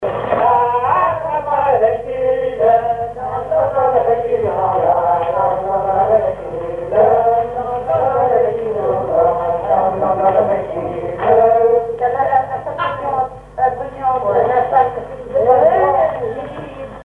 branle
Couplets à danser
Veillée de chansons
Pièce musicale inédite